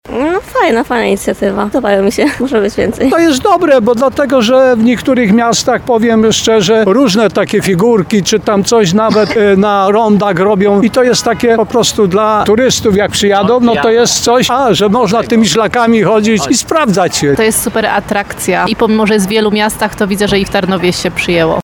25sonda_maszkarony.mp3